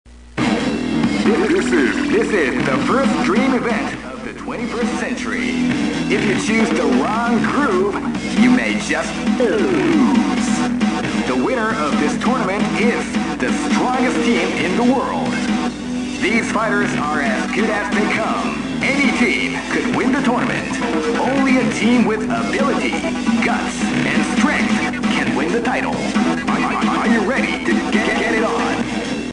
Sounds like a line from a J-Pop song...